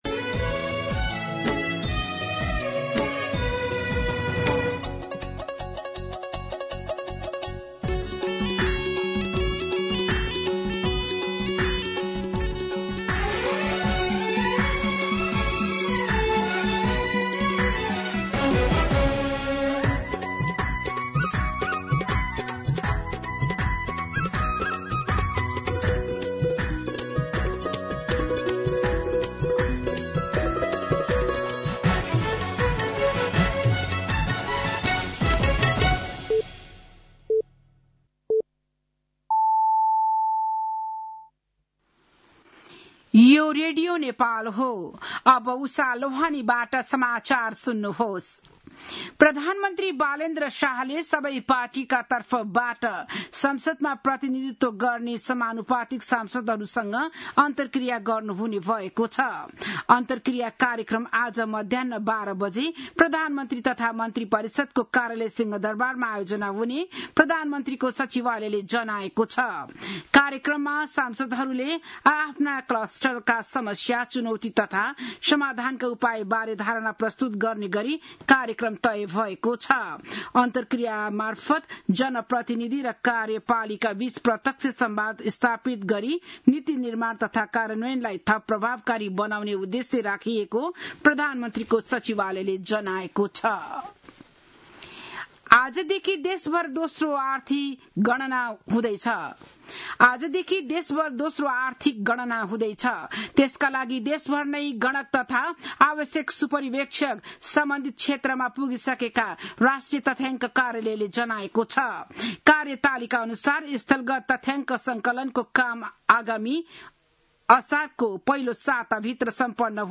बिहान ११ बजेको नेपाली समाचार : २ वैशाख , २०८३